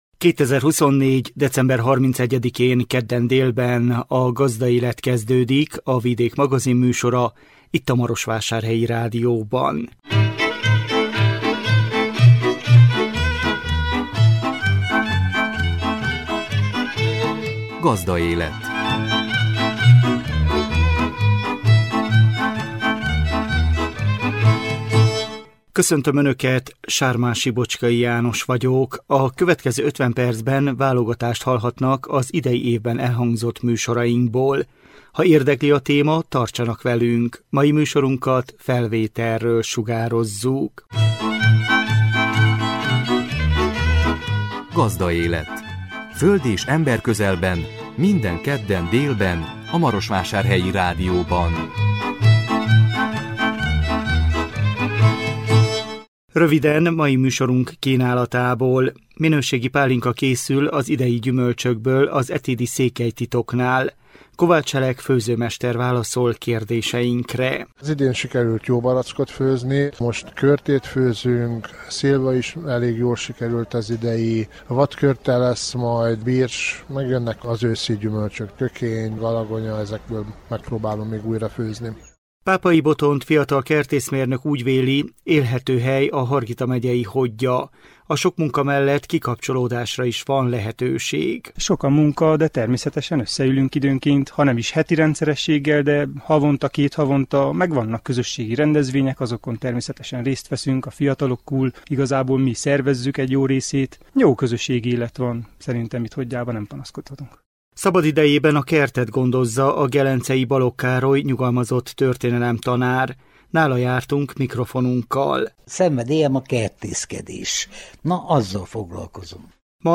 Nála jártunk mikrofonunkkal.